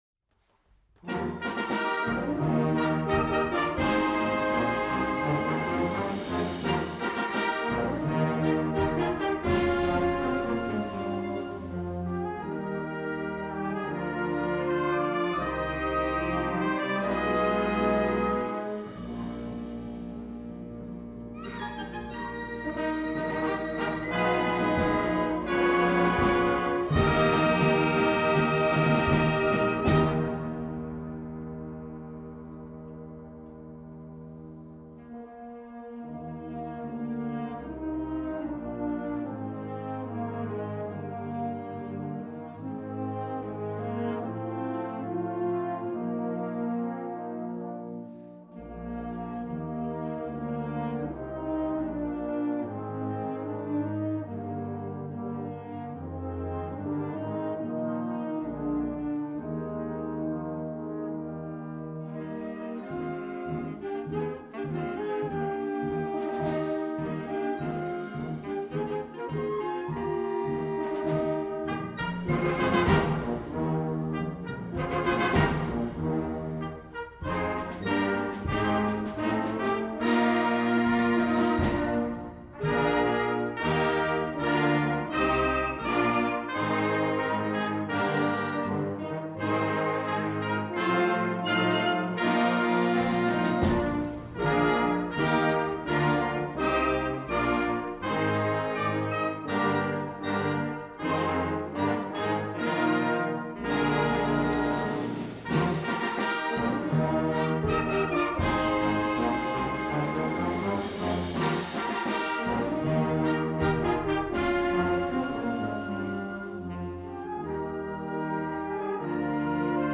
Ergebnis Konzertwertung